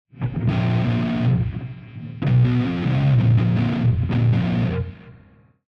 Also – while I could have edited the clips a little tighter – the hum from the CRT is present in the clips so this should be a realistic testing environment of what some one would get just pluging this into their laptop.
Here is the sound of a dirge type of riff played with the middle pickup settings.
schecter-omen-chunk-middle.mp3